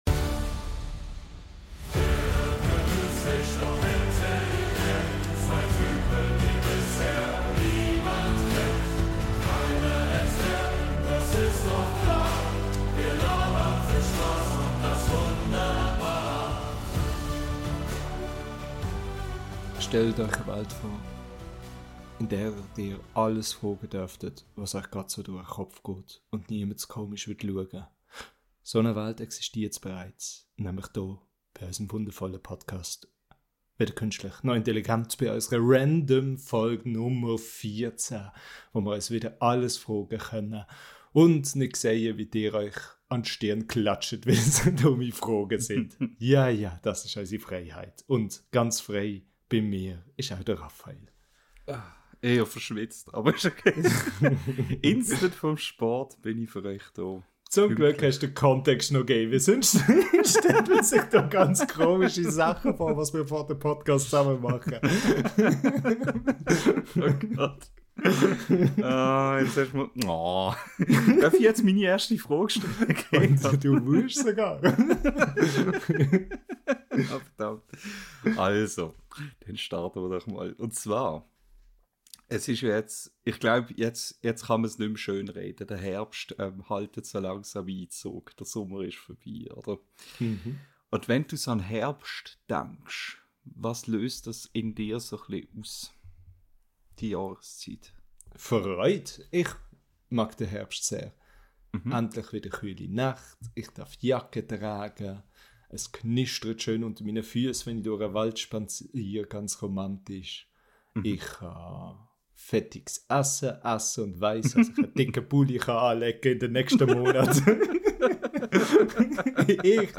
Willkommen zur 14. Random-Folge unseres schweizerdeutschen Podcasts